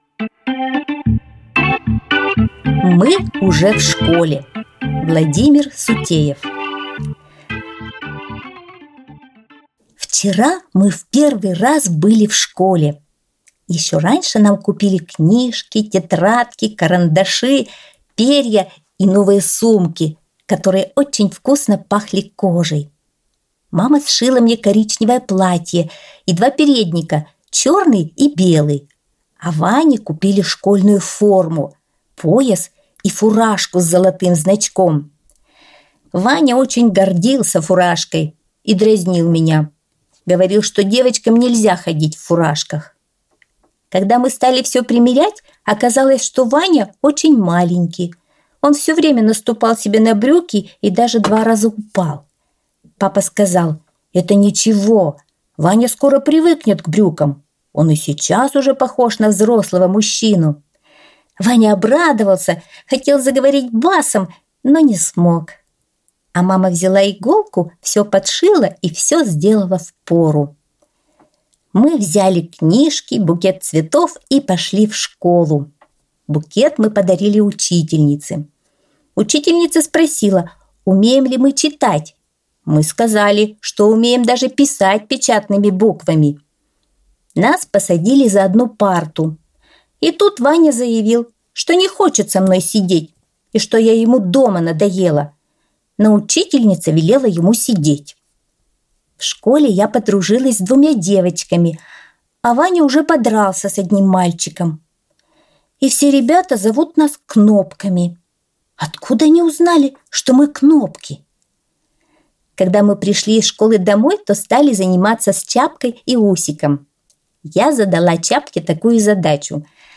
Аудиосказка «Мы уже в школе»